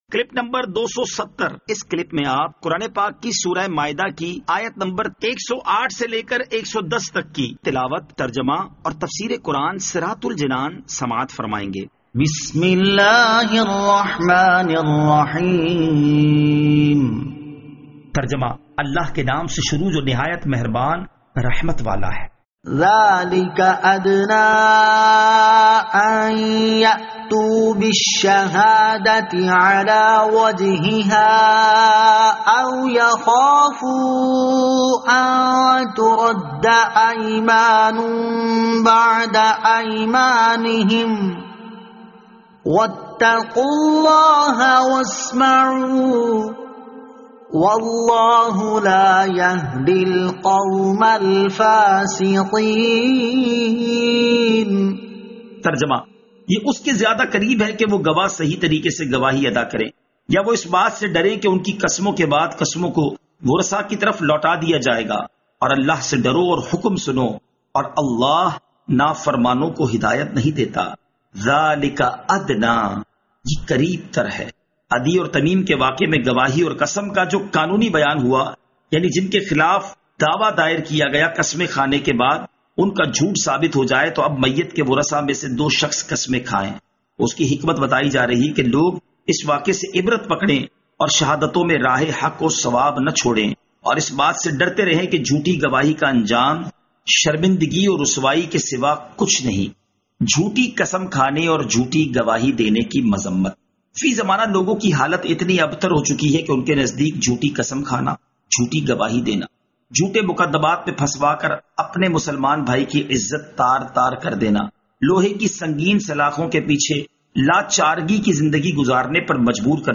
Surah Al-Maidah Ayat 108 To 110 Tilawat , Tarjama , Tafseer